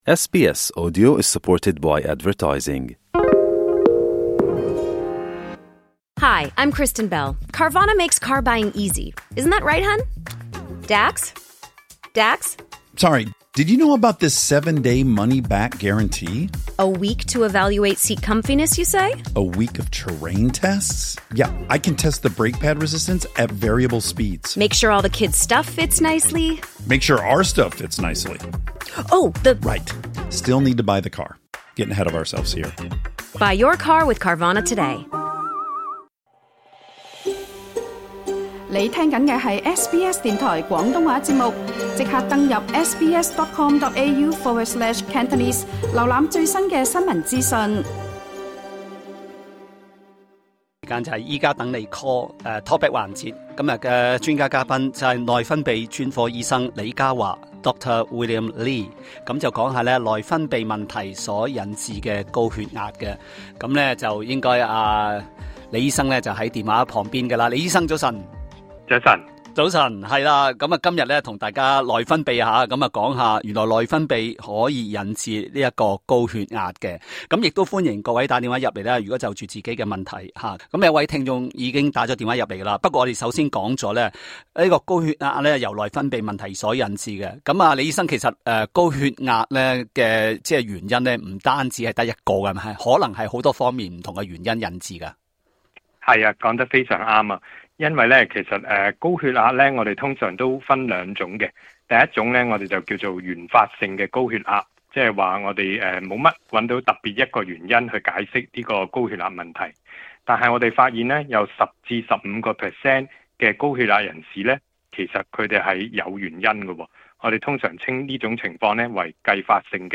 在本集《醫家等你Call》talkback 環節